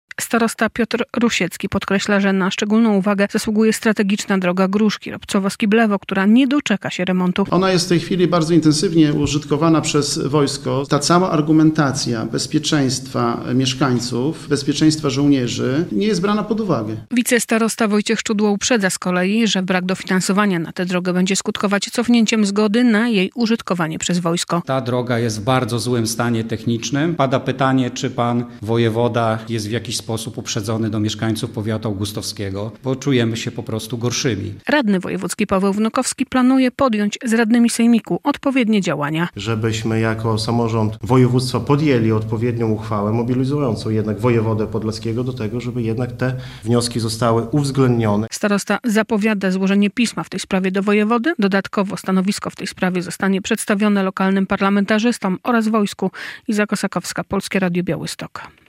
Podczas środowej (12.02) konferencji w Augustowie podkreślali, że czują się pokrzywdzeni.